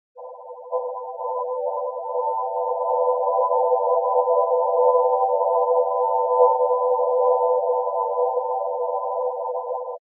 There has been no post-processing and no effects added.
Aurorae : ethereal drone made from curves contained in a narrow frequency band.